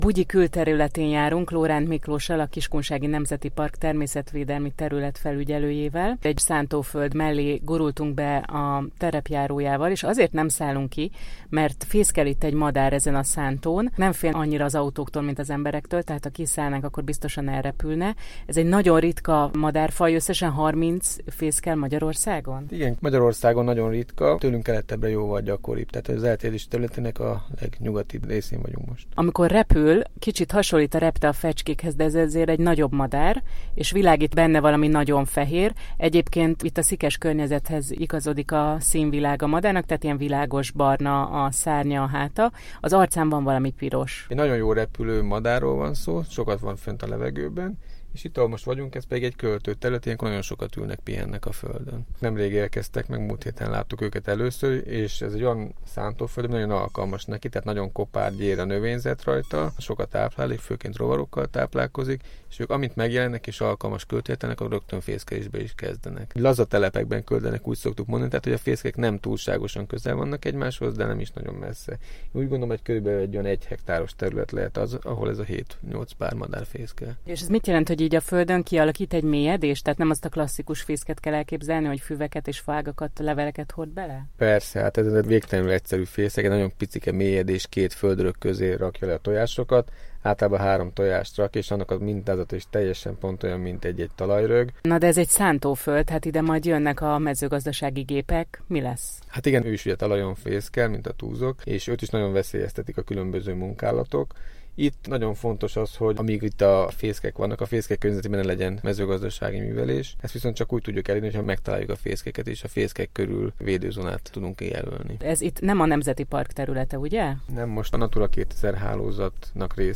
Several Radio Reports